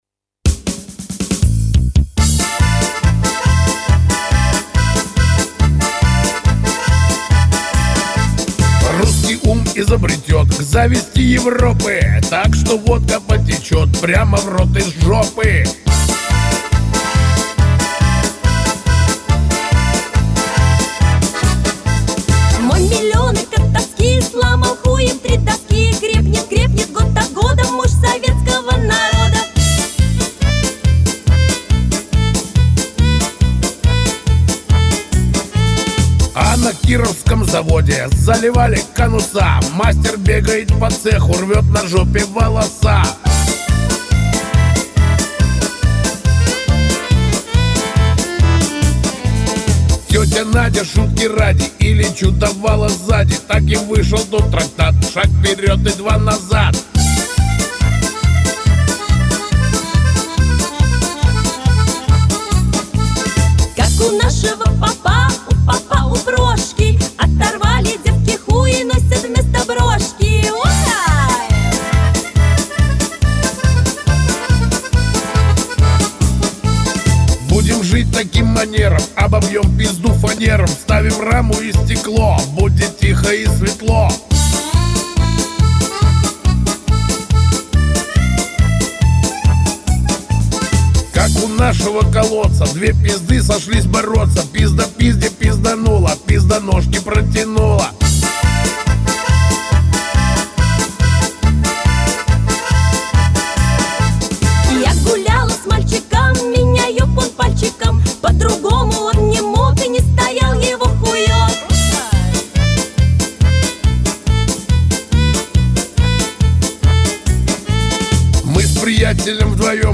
maternie_chastushki___sskij_um_izobretet_.mp3